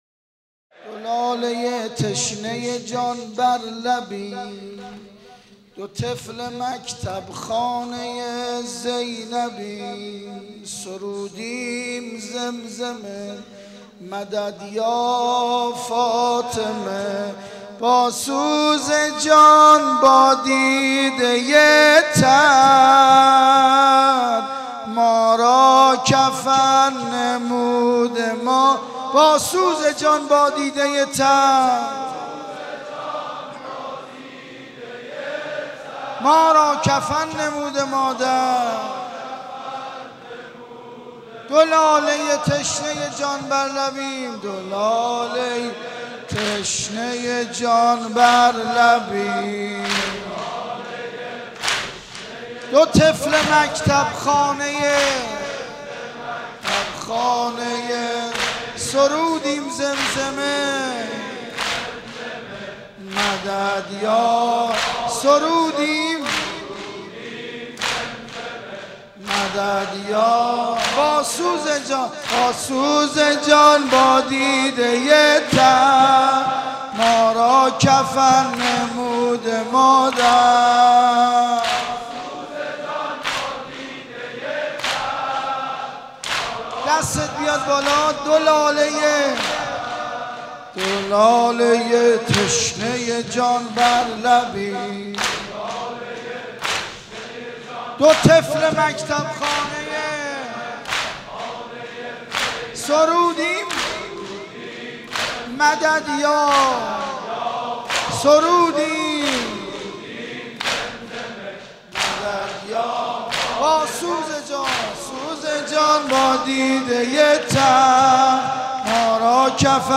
عقیق: مراسم شب پنجم محرم با حضور خیل عزاداران حسینی در مسجد امیر برگزارشد.
صوت مراسم
قسمت چهارم - ( نوحه )